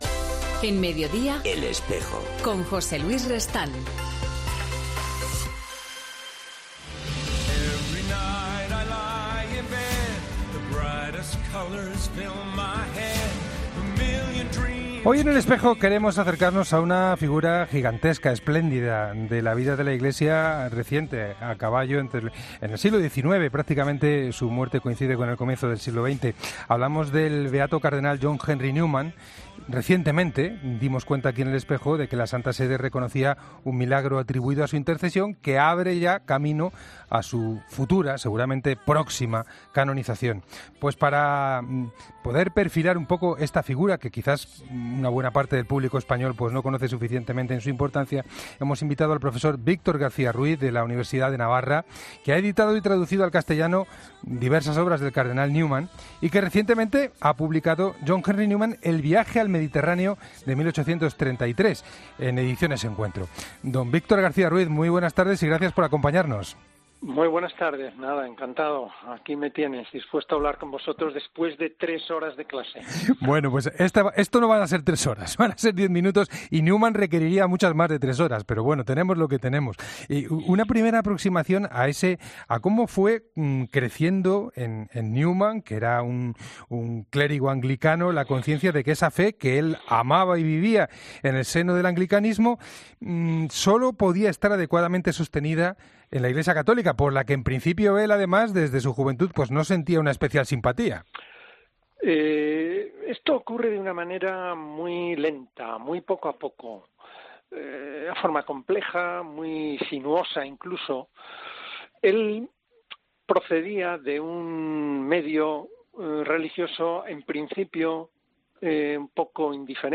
En el 'Espejo' de la Cadena COPE concedió una entrevista en la que habló sobre el futuro santo.